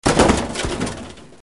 Metallo rimbalzante.
Rumore di bidone in metallo che rimbalza.
TRASHCN2.mp3